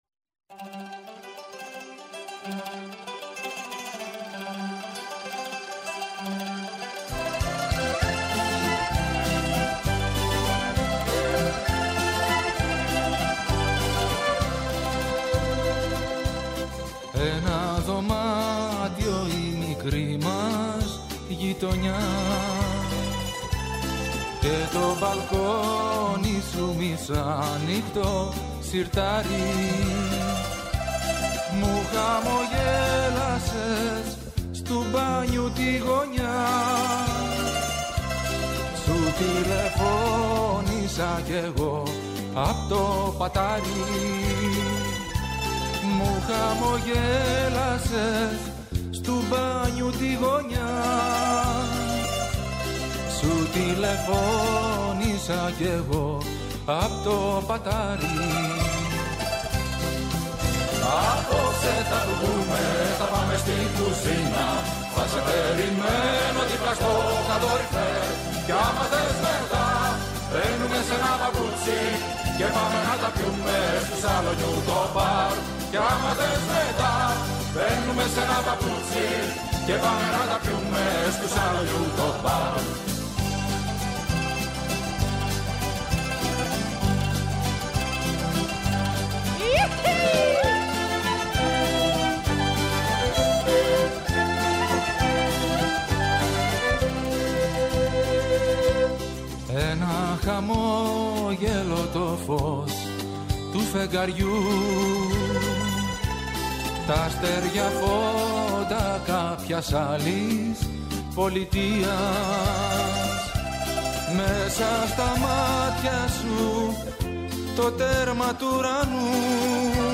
Μια εκπομπή με τραγούδια που αγαπήσαμε, μελωδίες που ξυπνούν μνήμες, αφιερώματα σε σημαντικούς δημιουργούς, κυρίως της ελληνικής μουσικής σκηνής, ενώ δεν απουσιάζουν οι εκφραστές της jazz και του παγκόσμιου μουσικού πολιτισμού. Η πορεία, οι σταθμοί και οι άνθρωποι που άφησαν τη σφραγίδα τους.